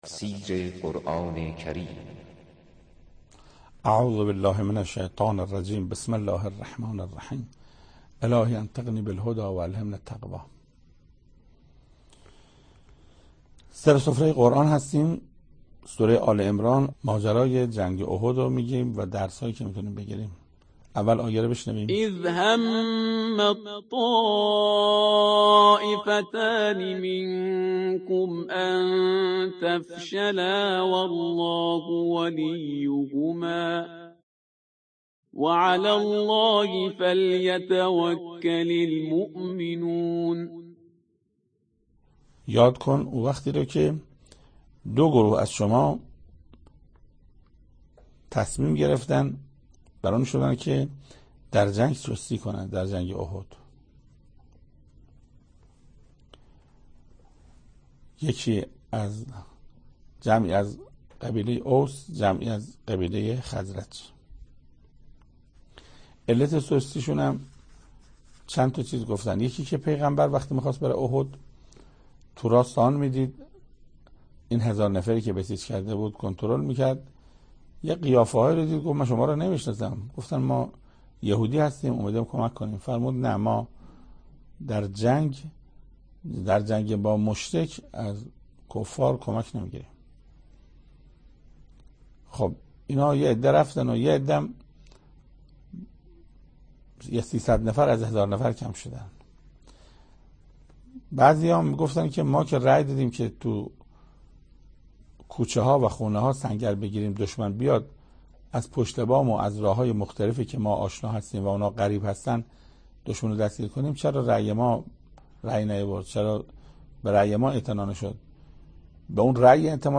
تفسیر صد و بیست و دومین آیه از سوره مبارکه آل عمران توسط حجت الاسلام استاد محسن قرائتی به مدت 7 دقیقه